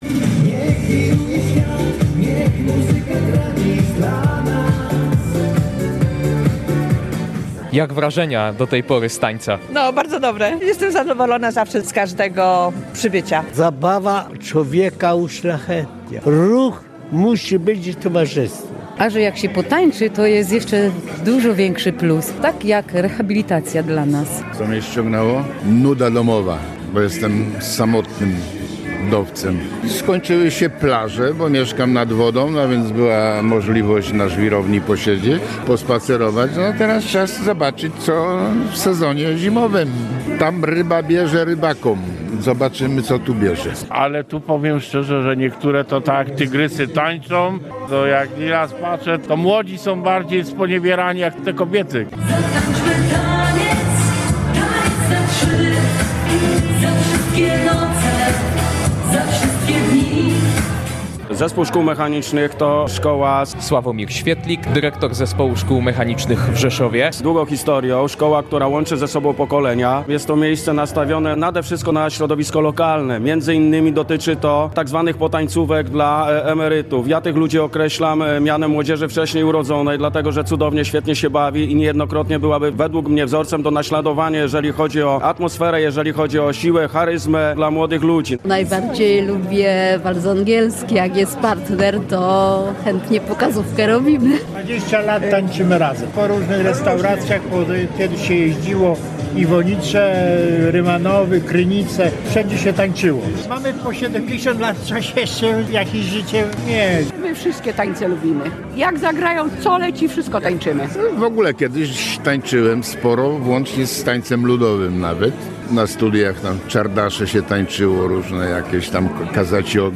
Około 150 seniorów uczestniczyło w sobotniej (8.11) zabawie tanecznej zorganizowanej w auli Zespołu Szkół Mechanicznych w Rzeszowie.